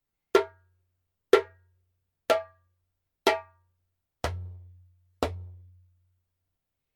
Wood 胴木材 : ゲニ (ハレ カディ バラフォン) Gueni (Hare Khadi Balafon)
コンパクトにぎゅっと凝縮した、くっきりと明るく、元気な音がします。
ジャンベ音